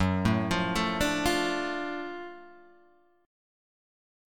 F#mM7#5 chord {2 0 3 2 3 1} chord